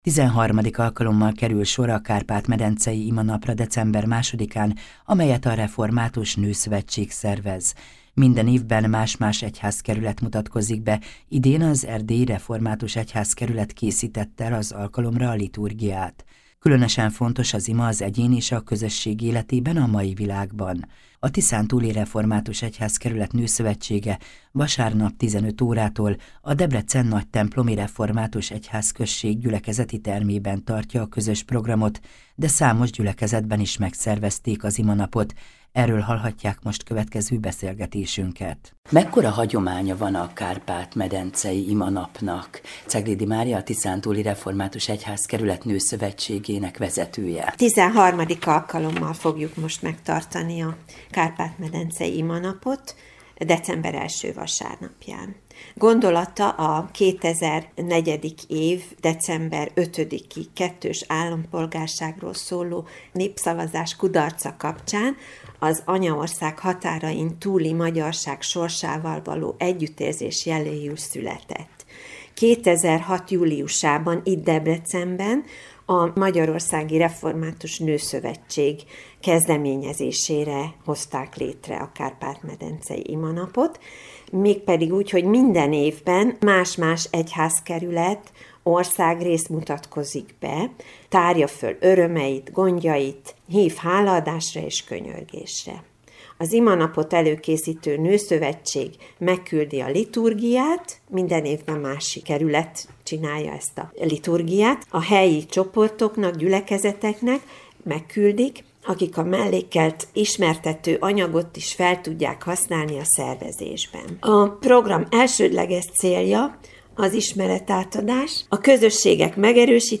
összeállítást az Európa Rádió ban.